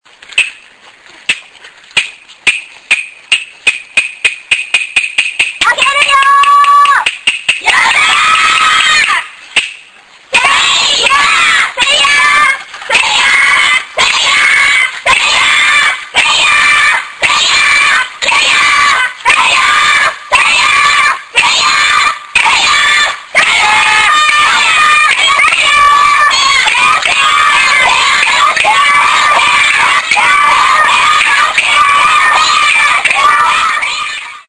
A large group of girls walked a temple through the streets, all the while chanting "Sayah Sayah", which I found out doesn't really mean anything, but if it did, it would mean "Go, Go".